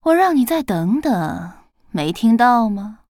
文件 文件历史 文件用途 全域文件用途 Cyrus_amb_01.ogg （Ogg Vorbis声音文件，长度3.1秒，102 kbps，文件大小：38 KB） 源地址:游戏语音 文件历史 点击某个日期/时间查看对应时刻的文件。